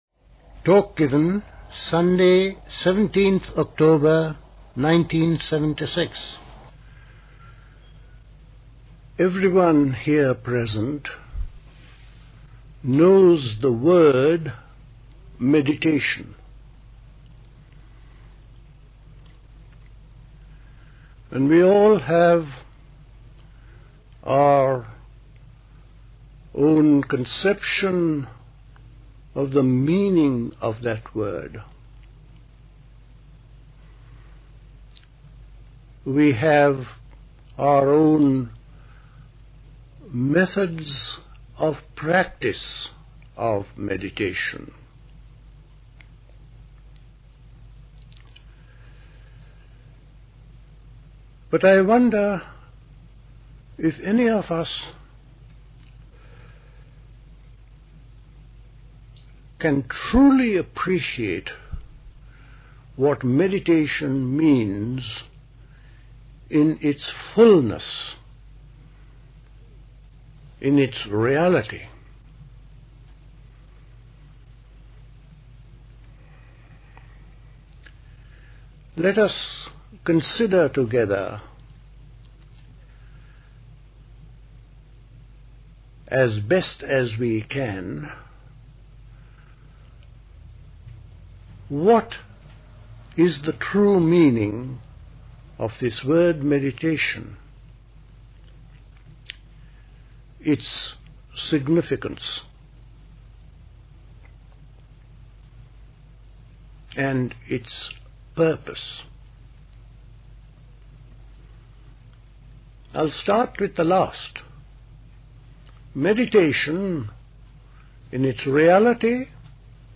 A talk
at Dilkusha, Forest Hill, London on 17th October 1976